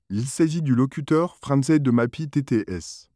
Supported voice names and samples generated with these models are also mentioned in the following table.
🔉FR-FR.Male.Male-1
FR-FR.Male.Male-1_MagpieTTS.wav